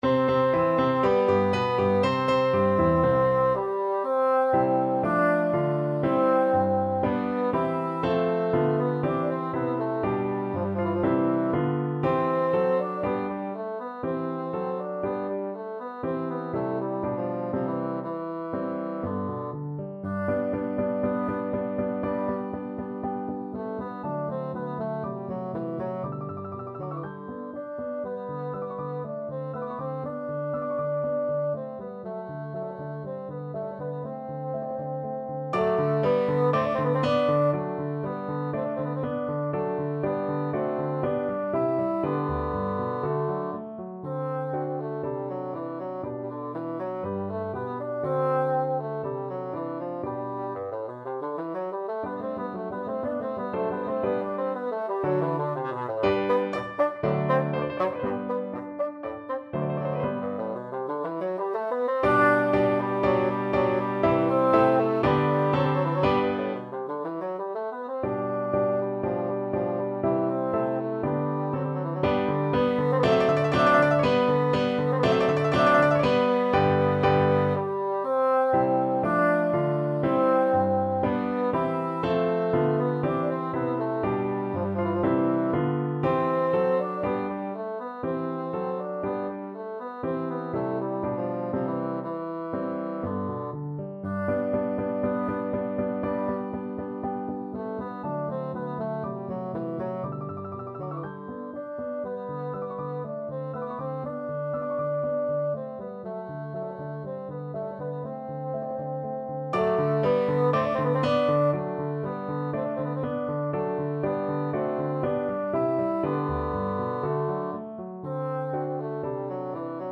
4/4 (View more 4/4 Music)
D3-G5
~ = 100 Allegro (View more music marked Allegro)
Classical (View more Classical Bassoon Music)